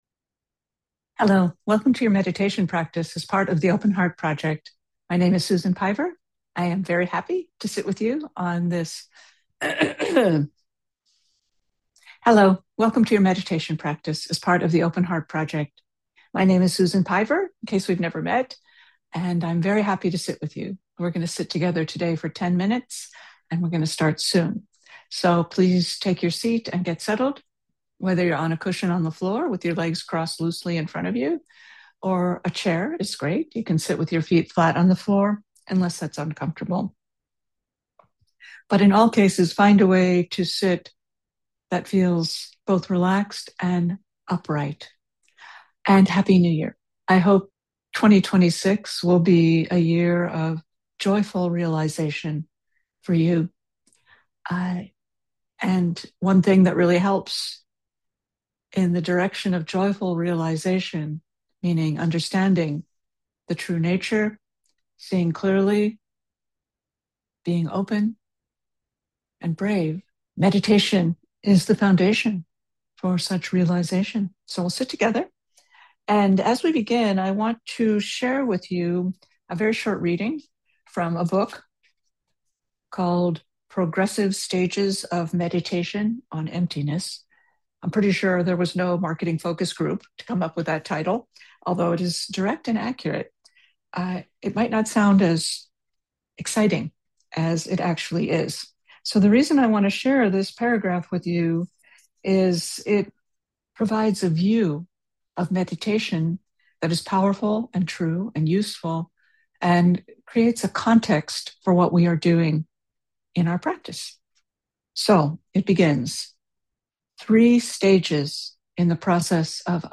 Audio only version is here Meditation begins at 8:39